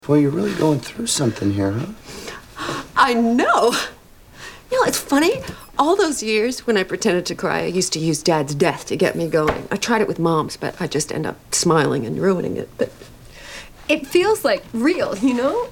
Category: Television   Right: Personal
Tags: Lindsay Bluth Funke Lindsay from Arrested Development Lindsay clips Arrested Development quote Portia de Rossi